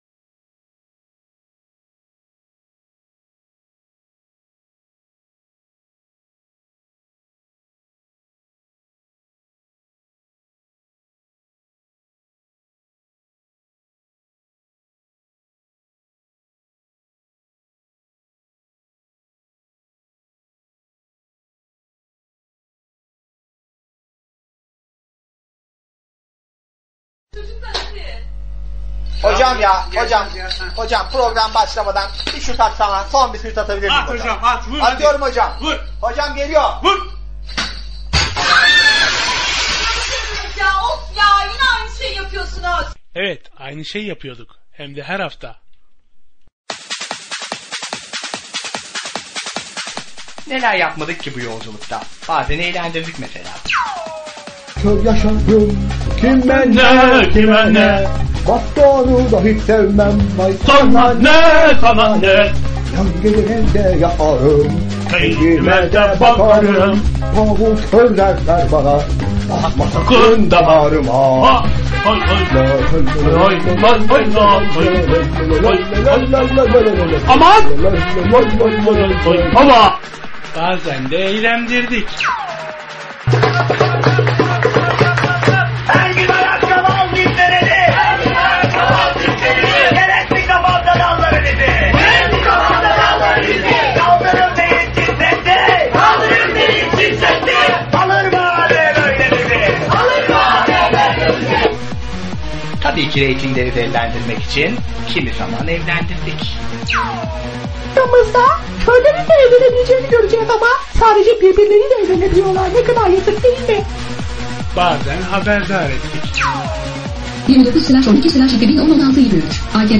Yine EGED radyodayız. bayağıdır süren teknik sorunları aştı EGED ekibi ve bize muhteşem bir alt yapı hazırladı.